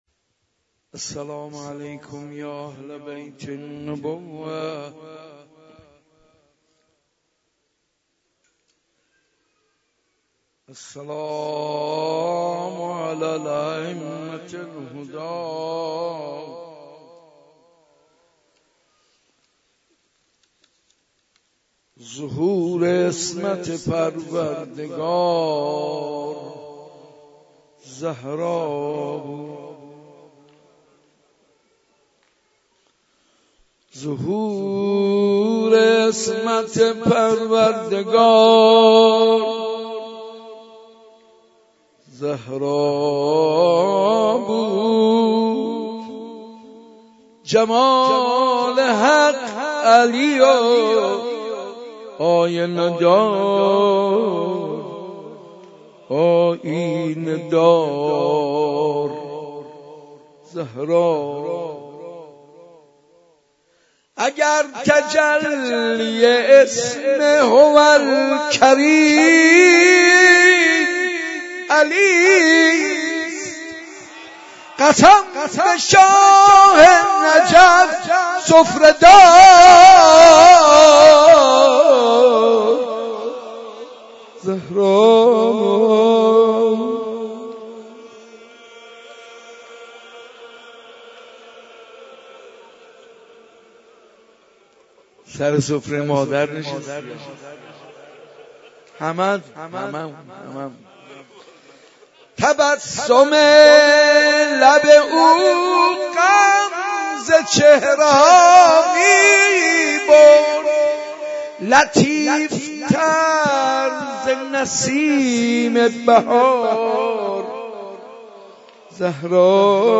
صوت شب دوم مراسم عزاداری فاطمیه اول مسجد ارک 94 با نوای حاج منصور ارضی منتشر می شود.